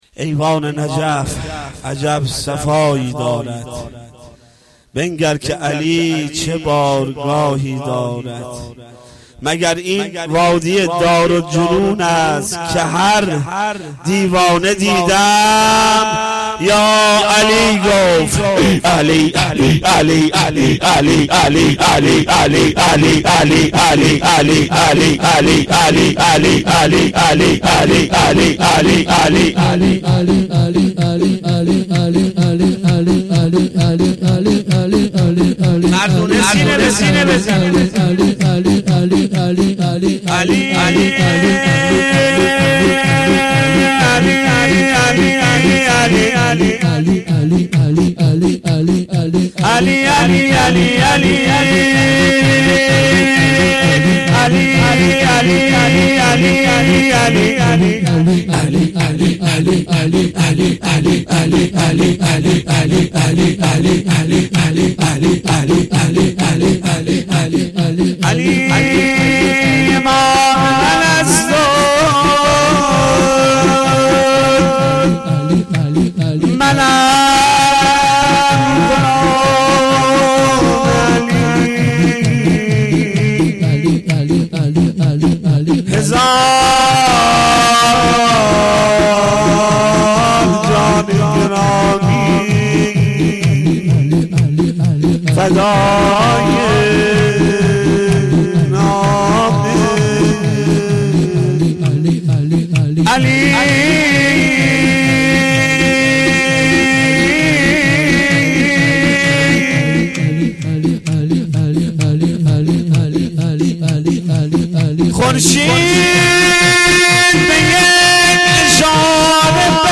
شعرخوانی